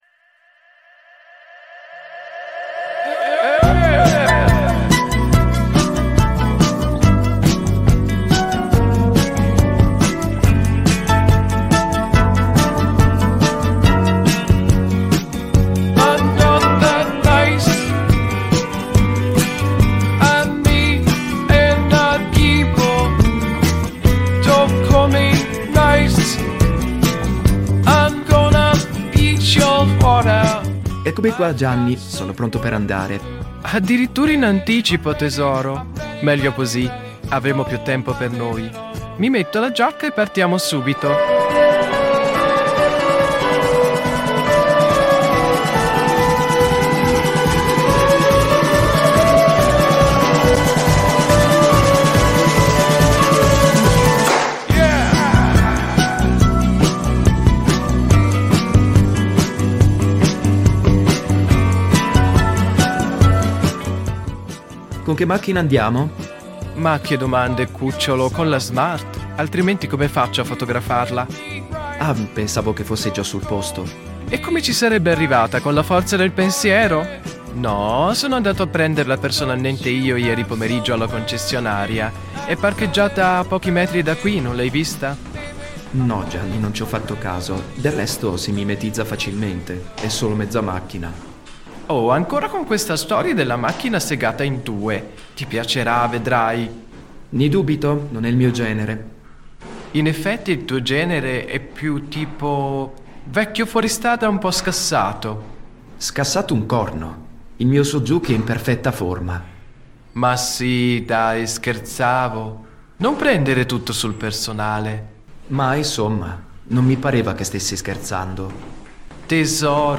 Gli interpreti sono Anthony, Paul Emmanuel, Peter e Betty (tutti AI).